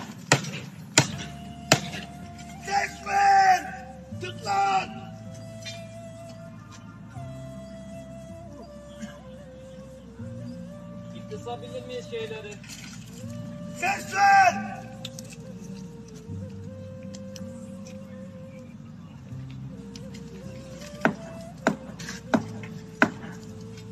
Deprem mağdurlarının sosyal medyada paylaştığı videoları tarayıp ses dosyalarına dönüştürerek internet sitesine yükledik.